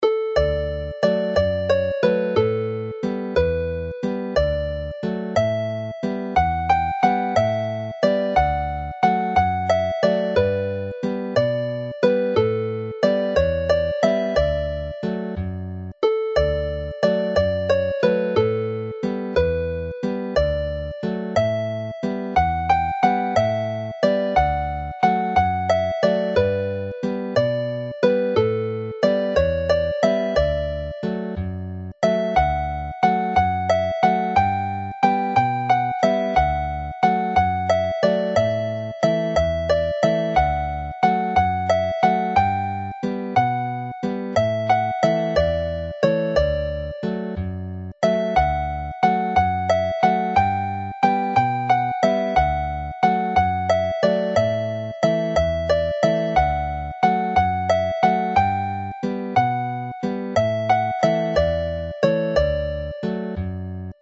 This set comprises jigs from the excellent collection of 100 dance tunes in the second book of tunes published by the Welsh Folk Dance Society, Cadw Twmpath.
Play the tune slowly